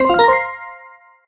join_game_room_01.ogg